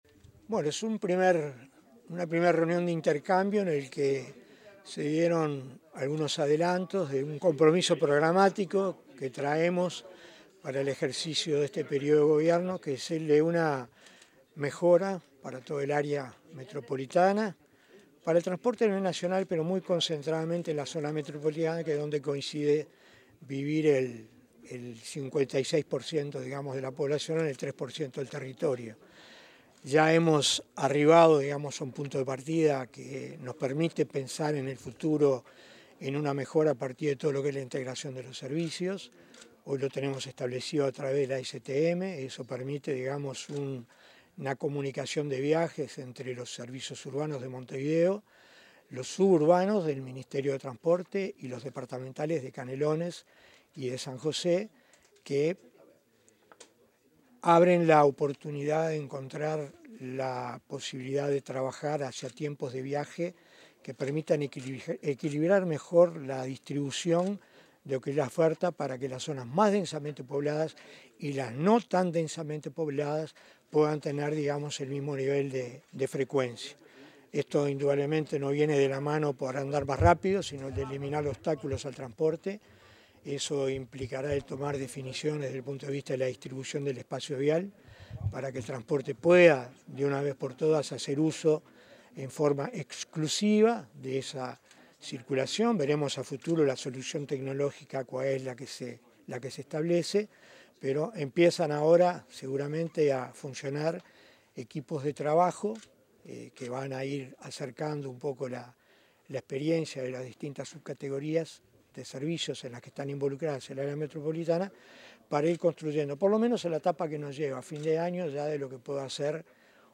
Declaraciones del director nacional de Transporte, Felipe Martín
El director nacional de Transporte, Felipe Martín, dialogó con la prensa una vez finalizada la reunión que mantuvo con representantes de empresas de